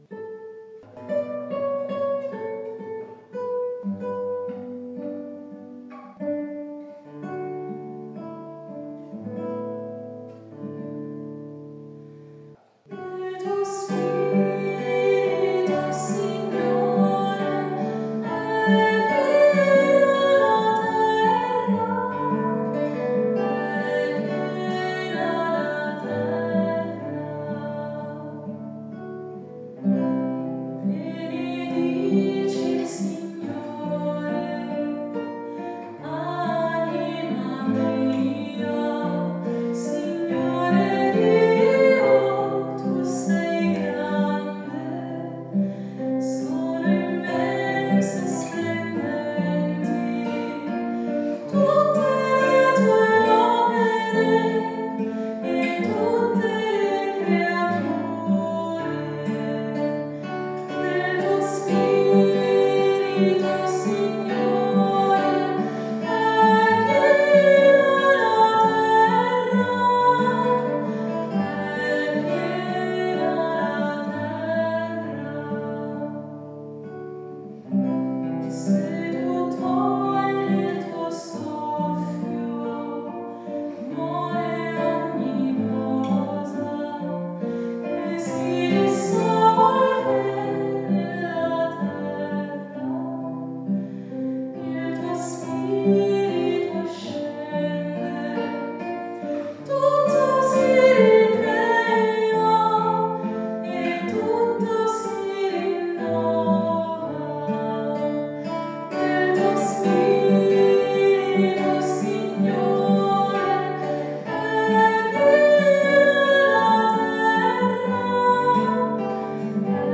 E’ un salmo di commozione e stupore per le meraviglie del Signore, che non solo crea ma, ogni giorno e in ogni celebrazione, richiama alla vita, rianima sempre, ridona l’armonia con la comunita’ e il Creato. Nel ritornello, la ripetizione di “e’ piena la terra” – da eseguire più piano della prima volta – e’ eco della potenza espressiva di questo salmo che collega, mirabilmente, la celebrazione della Pasqua con la Pentecoste.